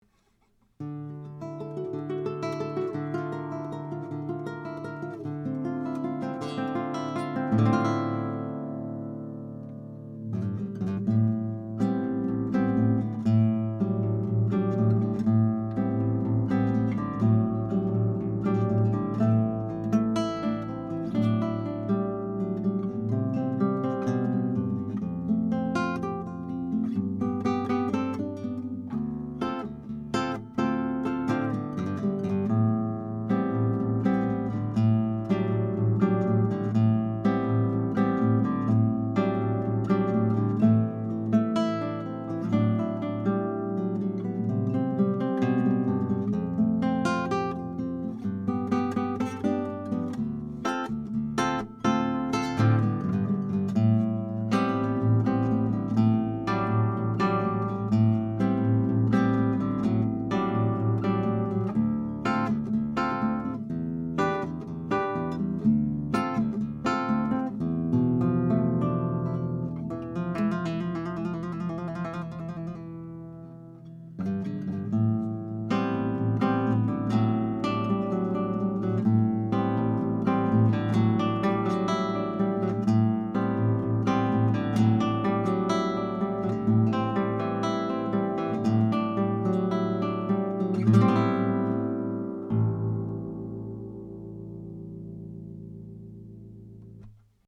The high end is tamed slightly, and the transients are smooth, with excellent off-axis rejection.
Tracked through a pair of Warm Audio WA12 preamps, into a Metric Halo ULN-8 interface, no compression, EQ or effects:
CLASSICAL HARP GUITAR